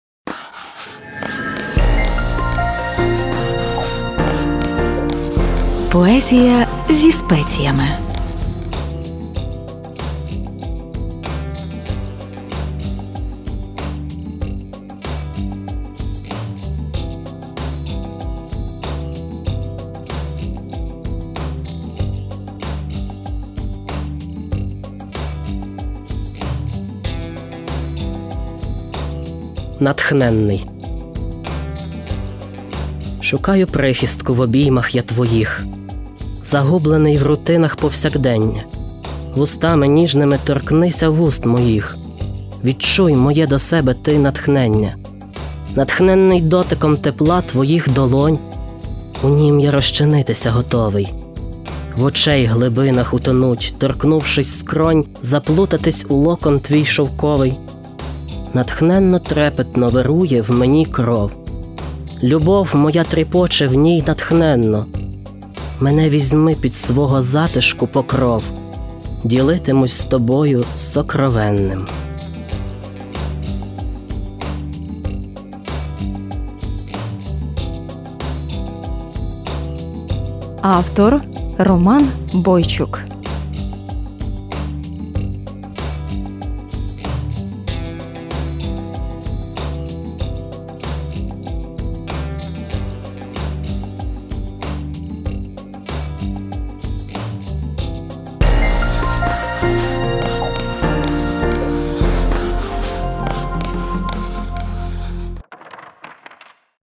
Натхненно! 12 Емоційно і проникливо. 16 Чудова декламація. 12 19 22 give_rose